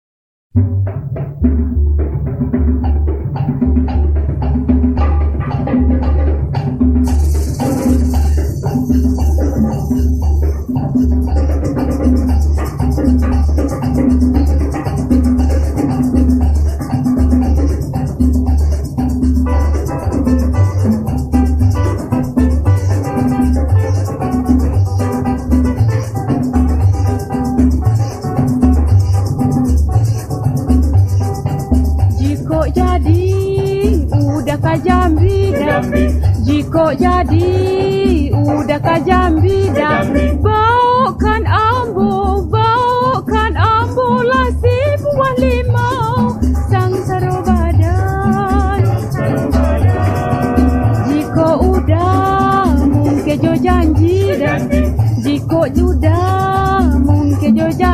西部スマトラはミナンのラテン系ムラユー楽団とで
こんなミナンなマンボ！？聴いたことありません！ ミナンなムラユー女声ボレロも最高です！